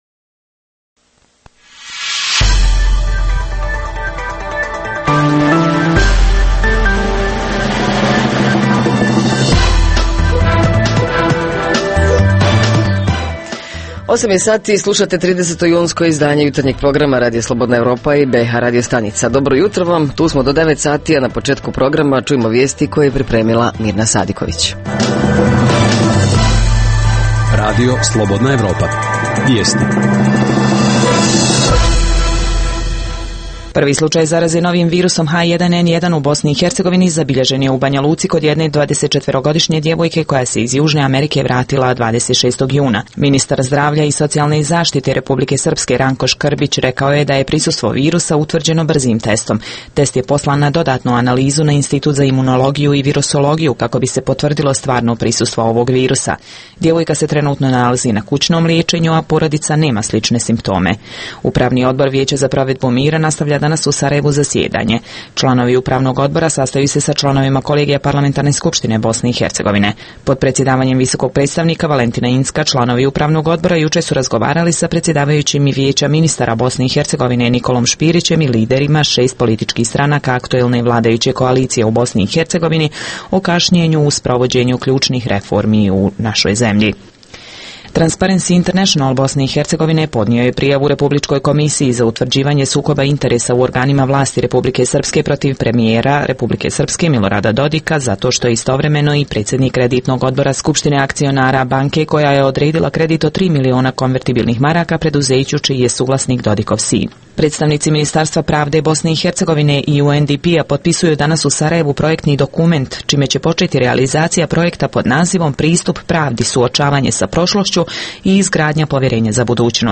Jutarnji program za BiH koji se emituje uživo. Tema jutra: kako se kontroliše sanitarno – higijenska ispravnost namirnica u ljetnim danima Reporteri iz cijele BiH javljaju o najaktuelnijim događajima u njihovim sredinama.
Redovni sadržaji jutarnjeg programa za BiH su i vijesti i muzika.